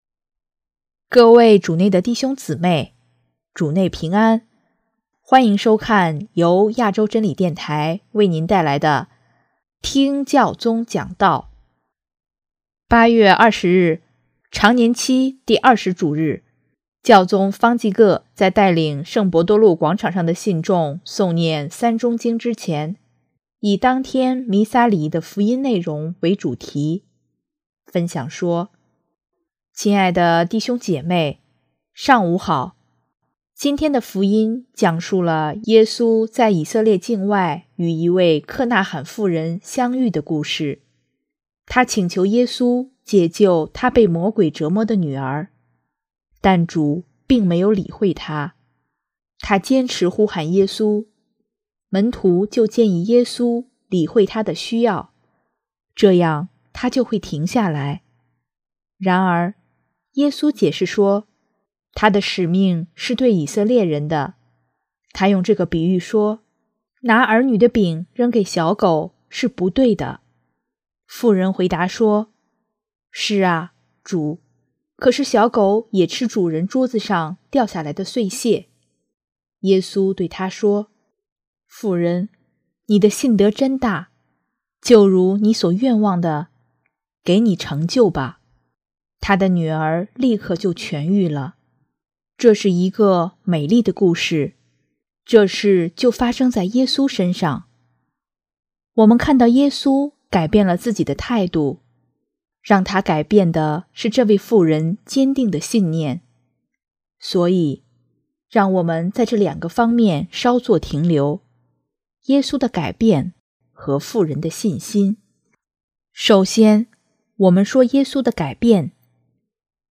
【听教宗讲道】|耶稣为什么改变了心意？
8月20日，常年期第二十主日，教宗方济各在带领圣伯多禄广场上的信众诵念《三钟经》之前，以当天弥撒礼仪的福音内容为主题，分享说：